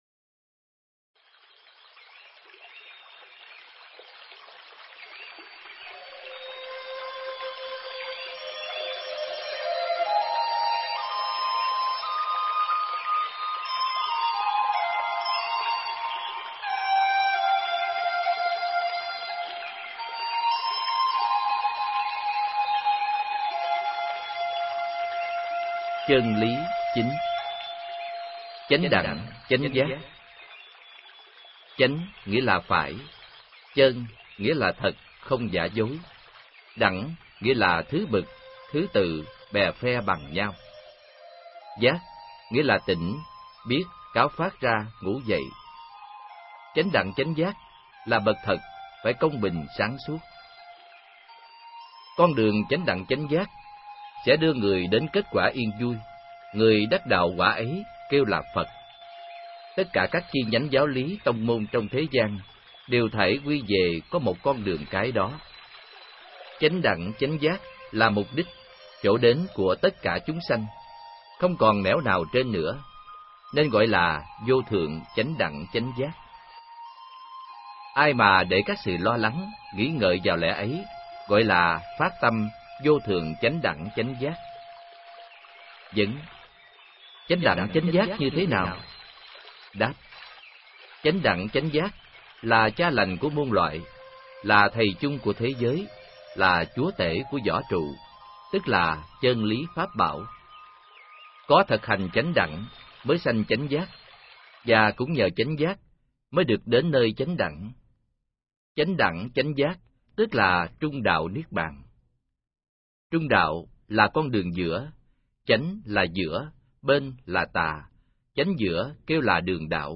Nghe sách nói chương 09.